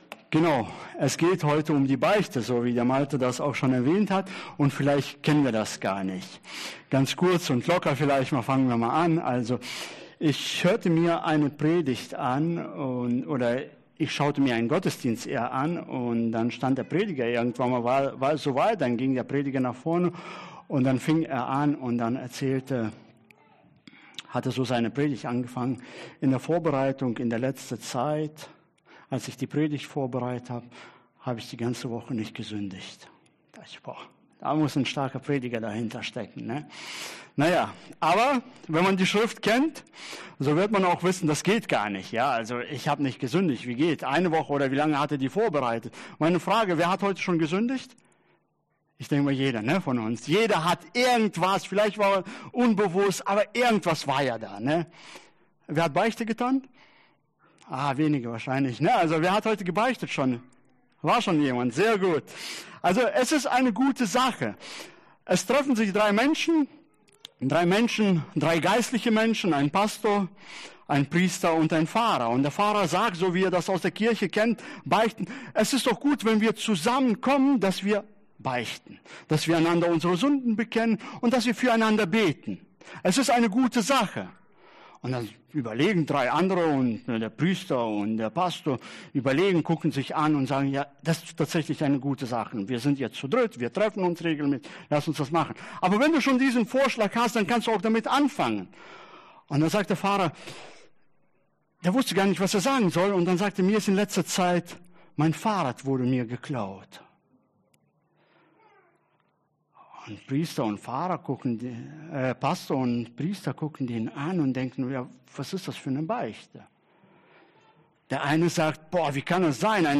Wie Umkehr echte Freiheit bringt Prediger